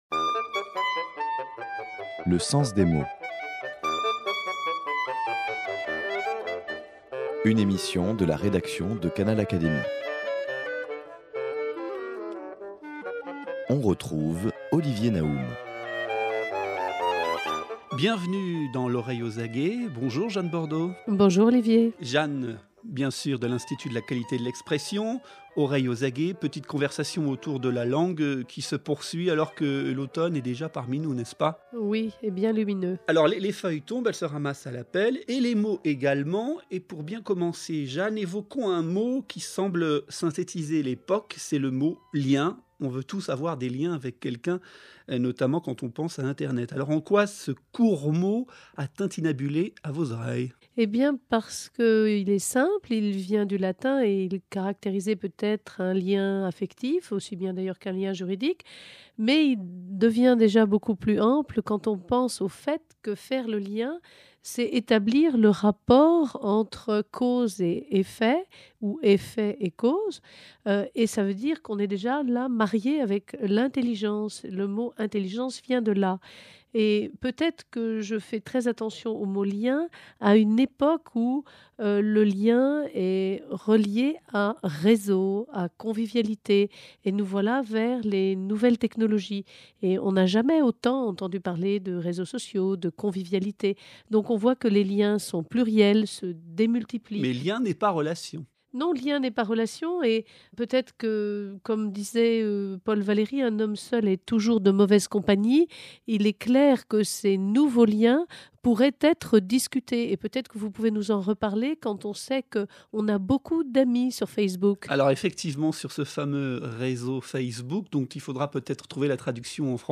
la chronique qui prend les mots à rebrousse-plume